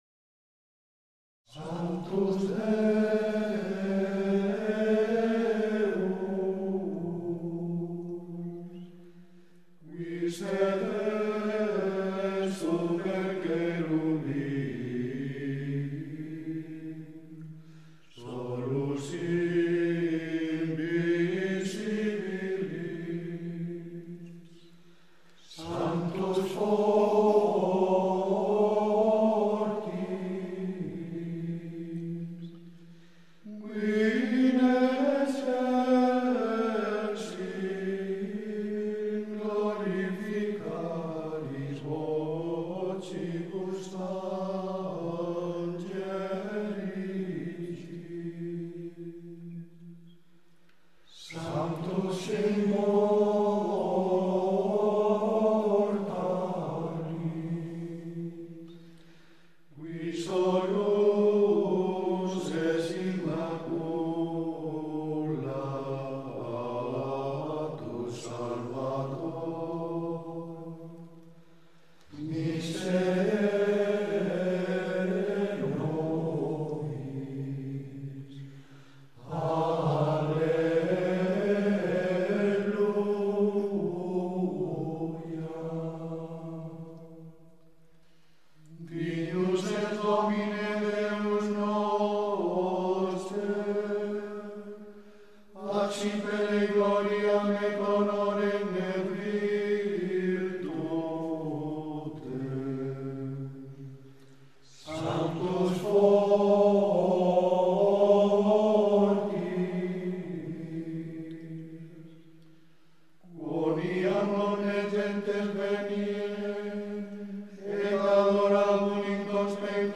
RITO HISPANO-MOZÁRABE
MÚSICA LITÚRGICA.